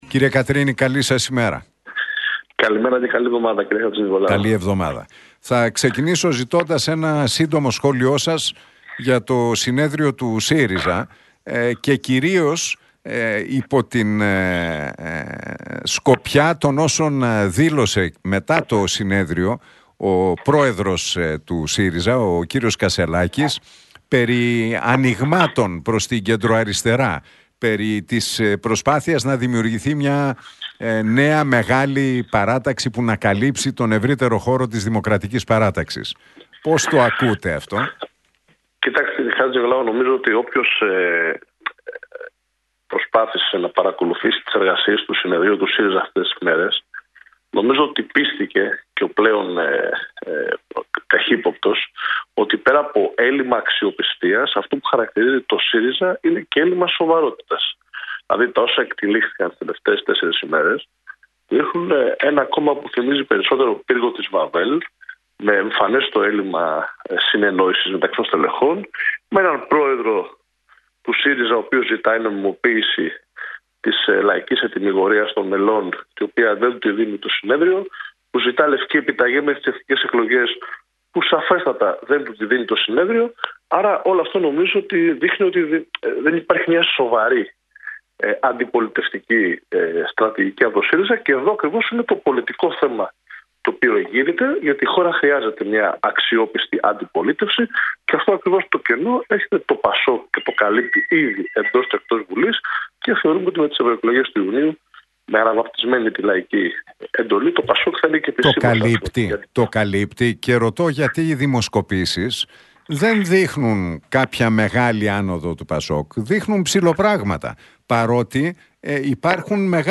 Τα όσα εκτυλίχθηκαν τις τελευταίες 4 ημέρες δείχνουν ένα κόμμα που θυμίζει περισσότερο πύργο της Βαβέλ με εμφανές το έλλειμμα συνεννόησης μεταξύ των στελεχών, με έναν πρόεδρο που ζητάει νομιμοποίηση της λαϊκής ετυμηγορίας των μελών, που δεν την δίνει το συνέδριο» τόνισε στον Realfm 97,8 και τον Νίκο Χατζηνικολάου, ο Μιχάλης Κατρίνης.